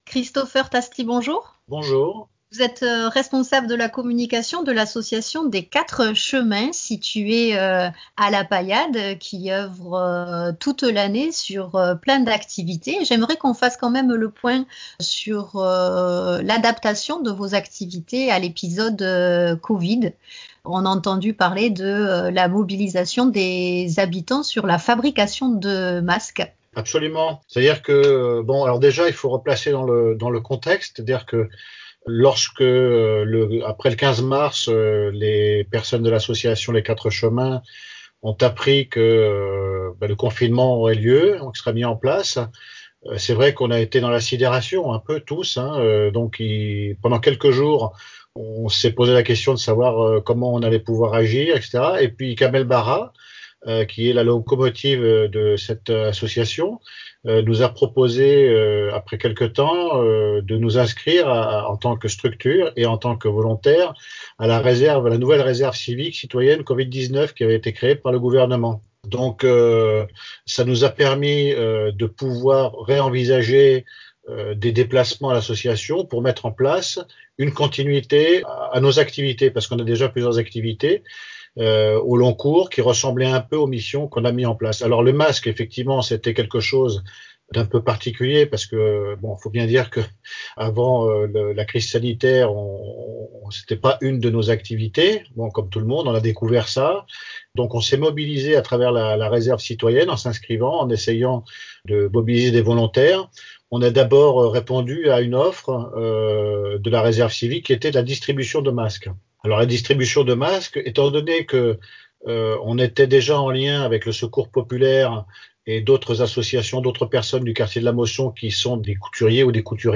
Emission diffusée en fin de période de confinement